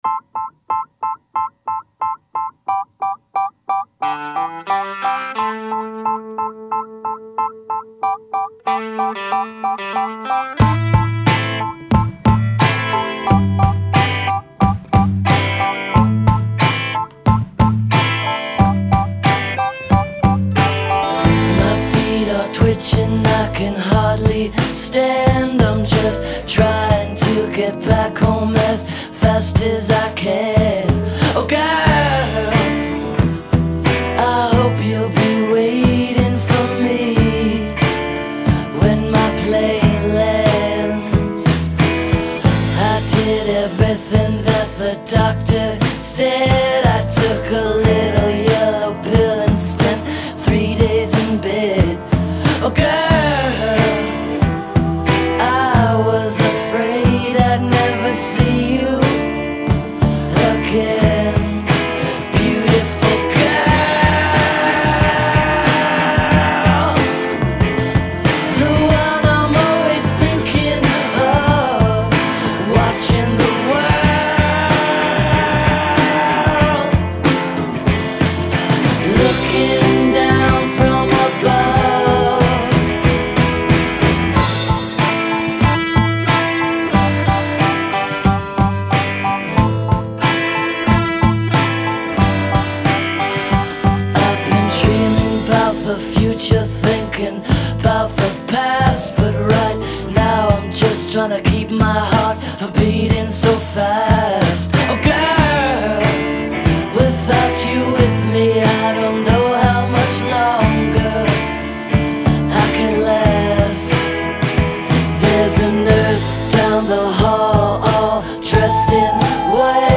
Great organ intro and a great overall song.